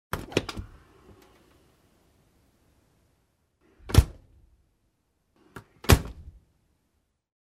На этой странице собраны звуки работающего холодильника: от монотонного гула до характерных щелчков и бульканья хладагента.
Звук открывающейся и закрывающейся дверцы холодильника